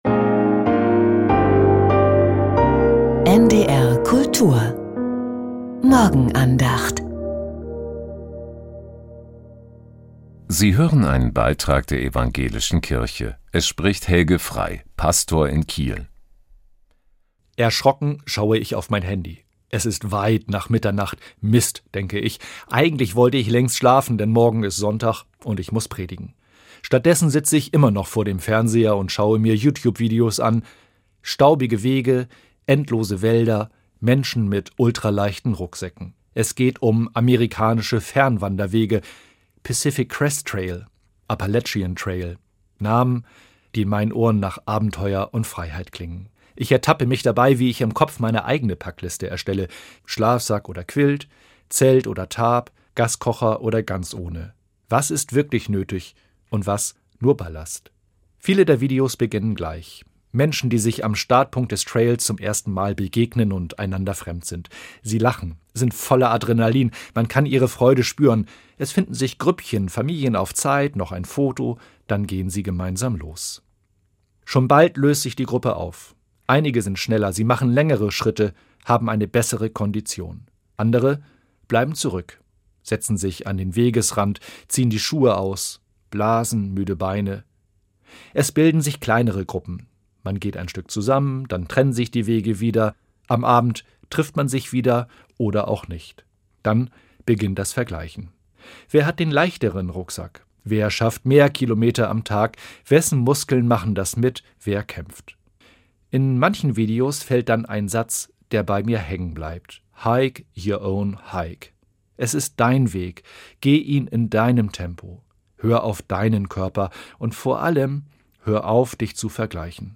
Die Andachten waren auf NDR Info und NDR Kultur zu hören.